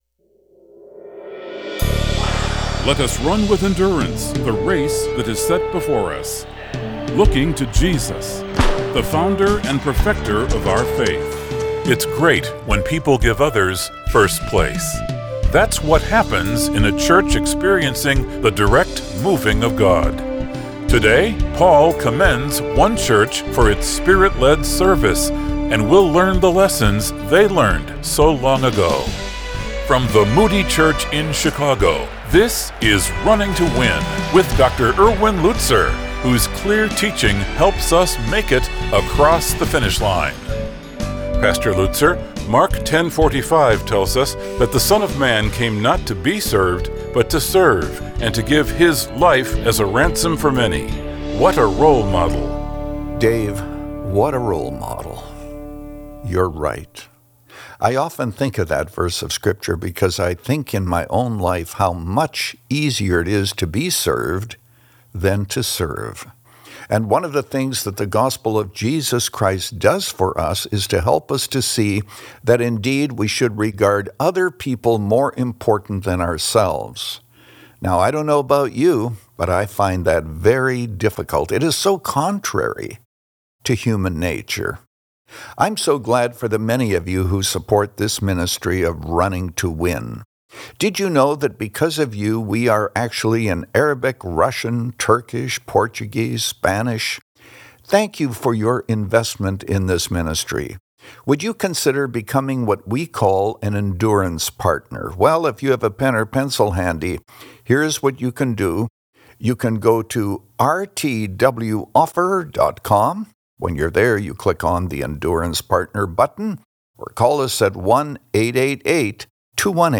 In this message from 1 Thessalonians 2, Pastor Lutzer describes the boldness and sacrifice of the early Christians’ proclamation of the Gospel. By putting ourselves aside, we reclaim a heart of service which puts others first.
But with the Bible front and center and a heart to encourage, Pastor Erwin Lutzer presents clear Bible teaching, helping you make it across the finish line. Since 2011, this 25-minute program has provided a Godward focus and features listeners’ questions.